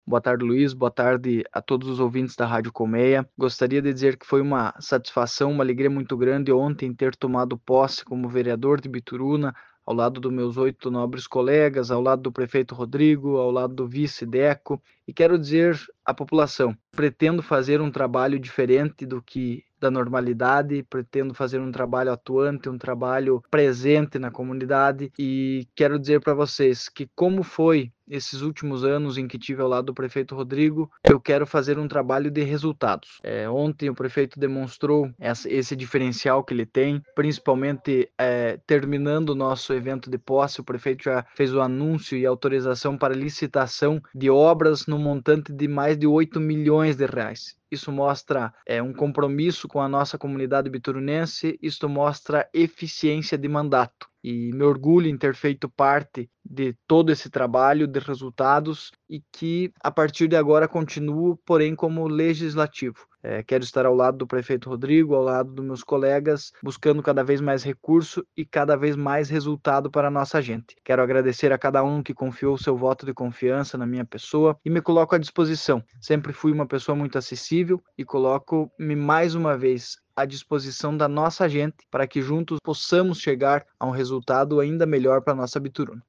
O vereador mais jovem a ser votado no município, Cleiton de Bastiani, também falou sobre as expectativas para este novo mandato que se inicia em 2025